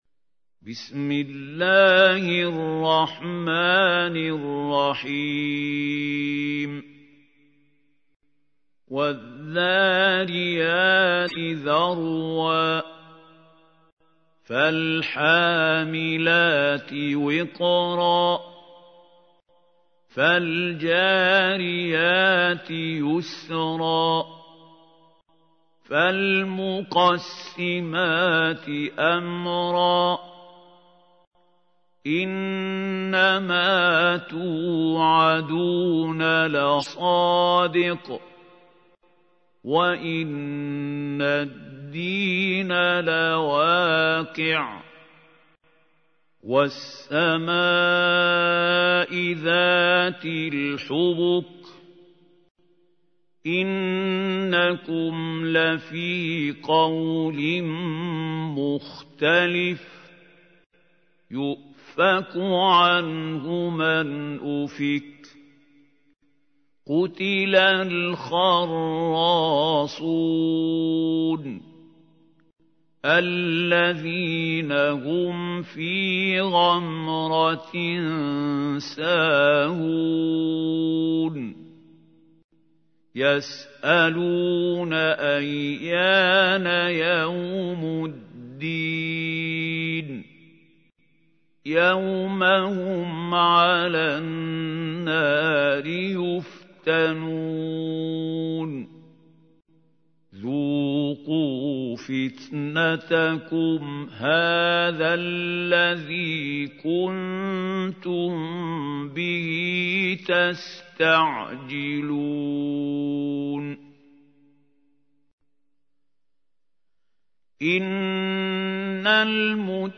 تحميل : 51. سورة الذاريات / القارئ محمود خليل الحصري / القرآن الكريم / موقع يا حسين